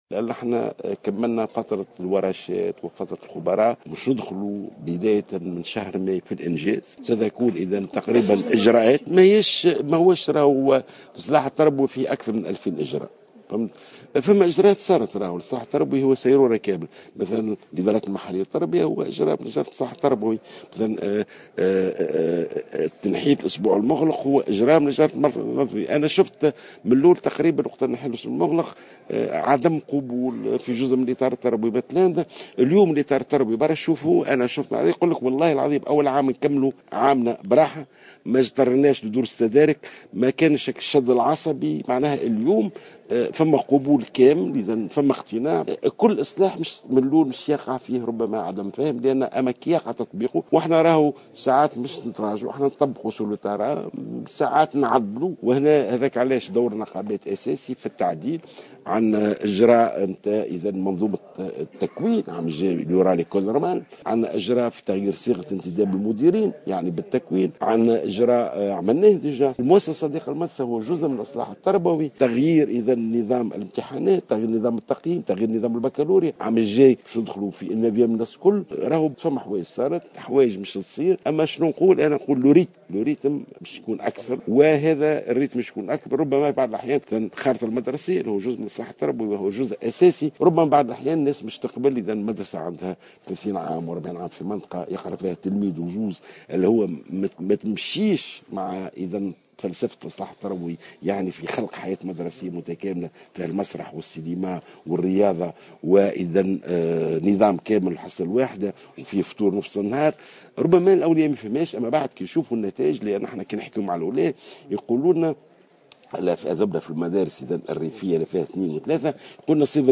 أكد وزير التربية ناجي جلول على هامش اختتام الندوة الوطنية المخصصة للحوار حول اصلاح المنظومة التربوية أن الإجراءات التي تضمنها برنامج الإصلاح التربوي سينطلق تنفيذها بداية من شهر ماي المقبل .